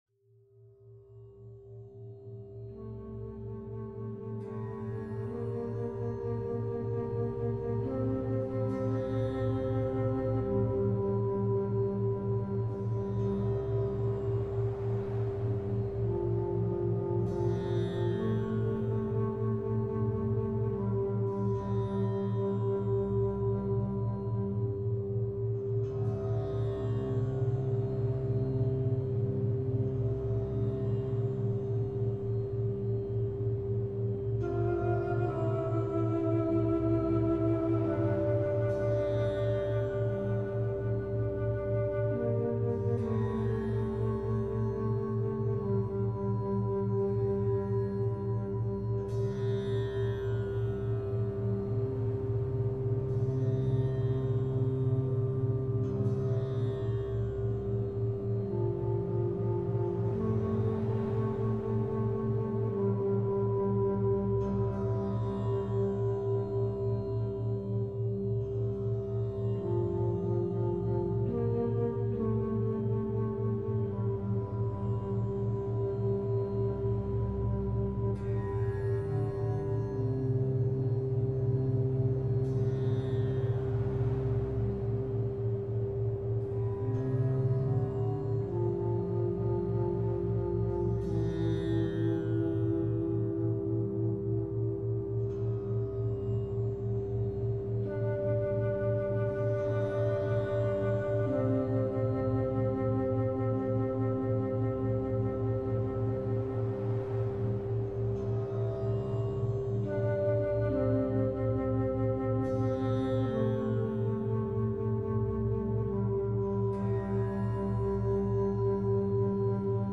Heart Chakra Meditation – 512 Hz Sound for Emotional Healing